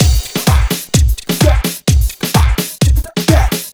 128 Body Groove Full.wav